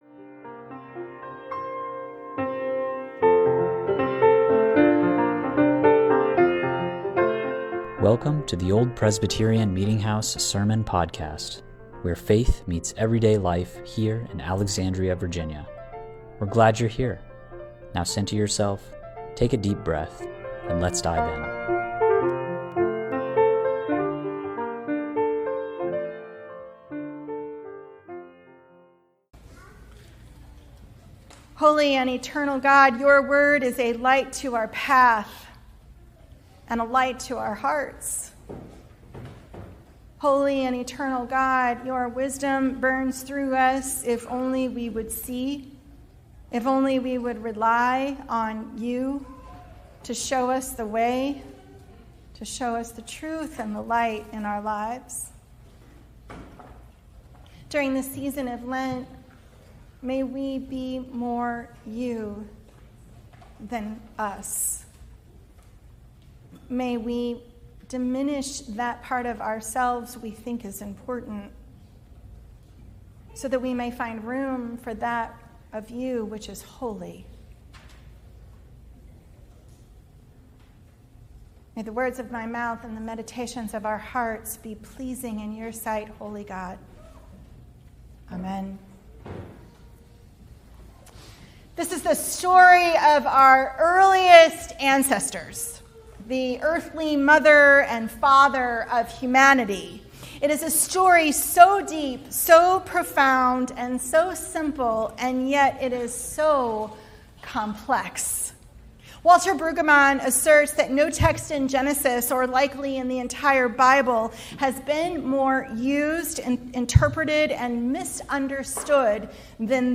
Old Presbyterian Meeting House Sunday Sermon “What is Sin?”